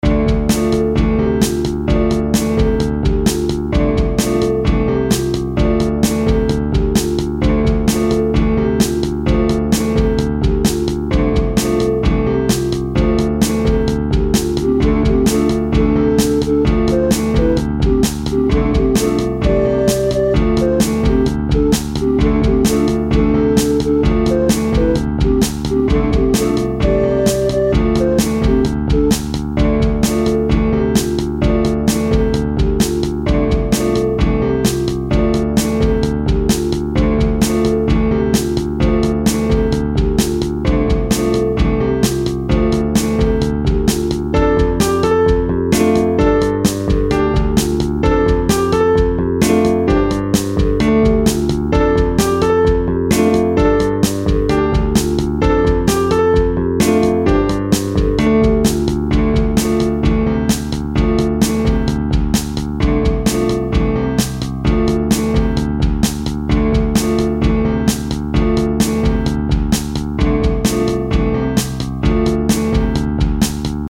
Some SNES style music
I pulled together a bunch of cheezy, fake-sounding samples to use that sounded about right.
It's subtle, but it makes the over-all sound seem "bigger".
I reused the arpeggio from the first track, so that will represent some sort of tie-in between the two areas.
These are intended to loop indefinitely in the video game, so they end abruptly.
Filed under: Instrumental